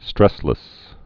(strĕslĭs)